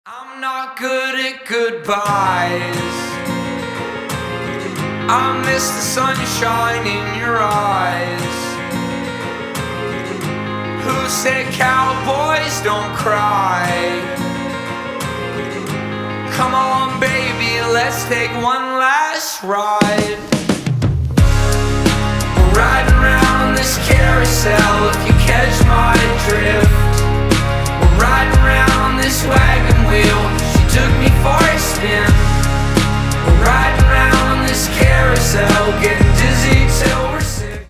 Рок Металл
спокойные